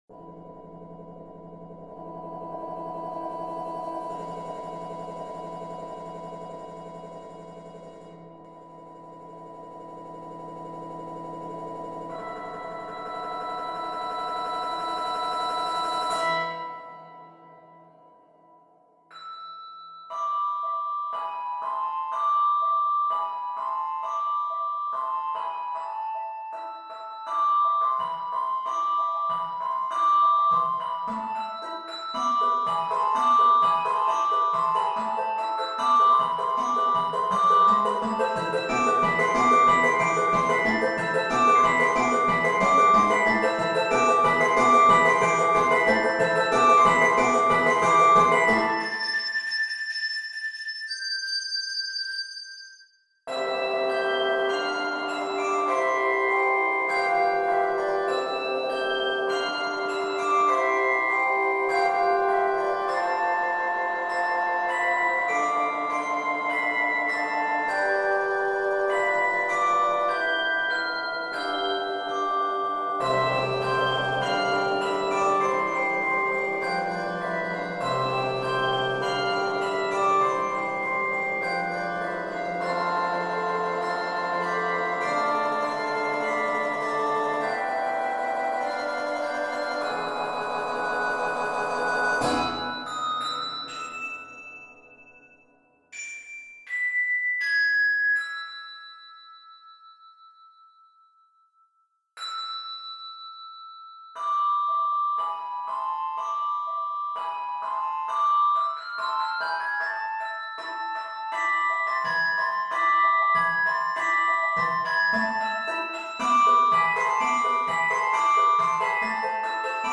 folk song
Key of a minor. 84 measures.